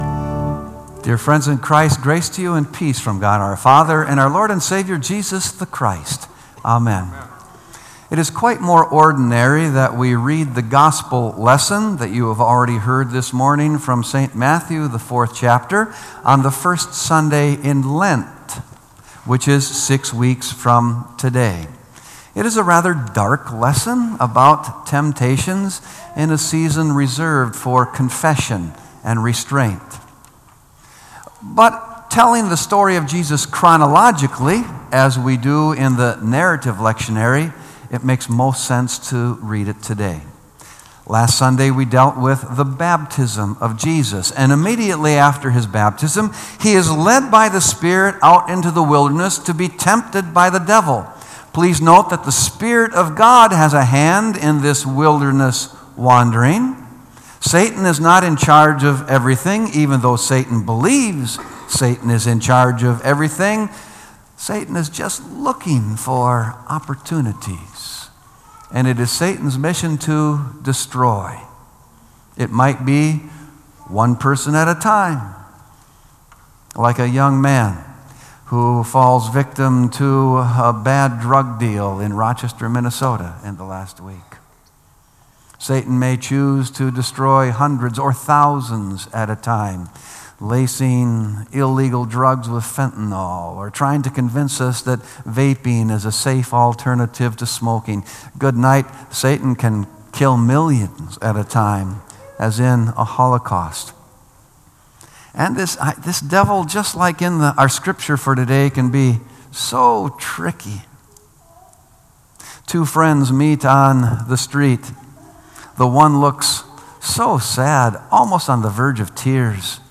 Sermon “Satan Sunday” | Bethel Lutheran Church